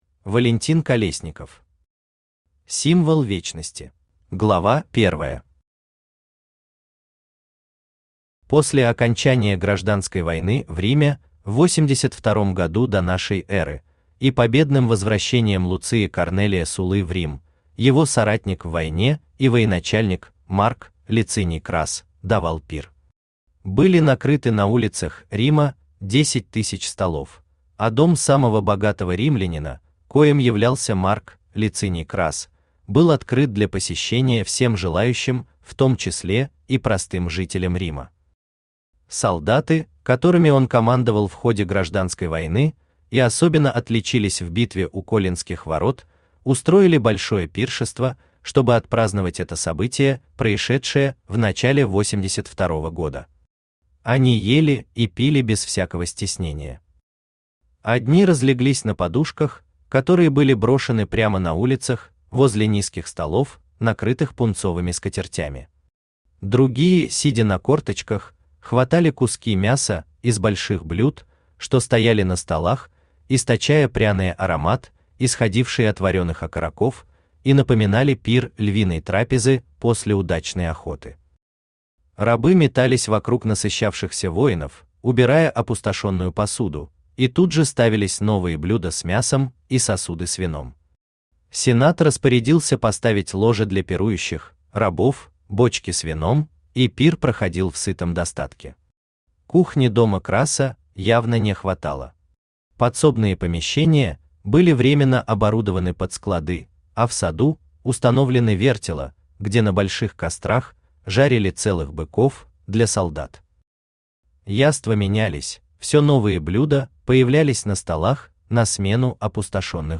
Аудиокнига Символ Вечности | Библиотека аудиокниг
Aудиокнига Символ Вечности Автор Валентин Колесников Читает аудиокнигу Авточтец ЛитРес.